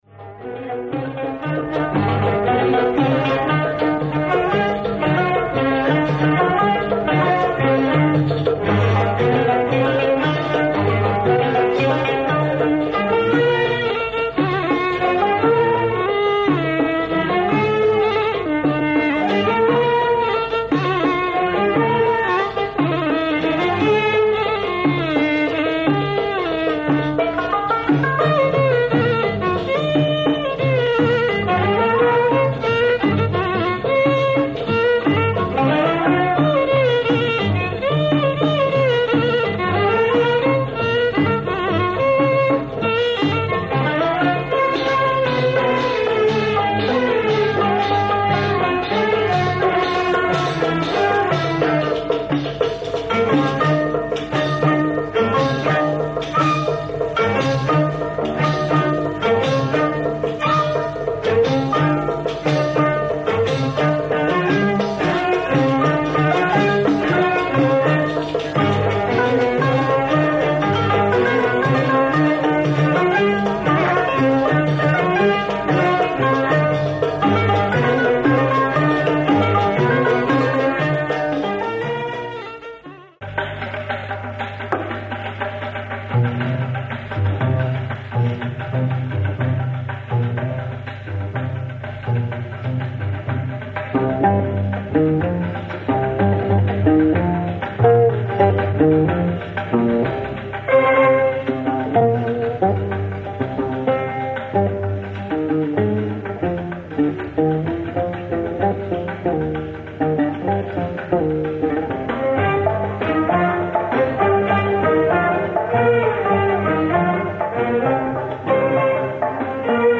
Marvellous Moroccan Mizrahi track on b-side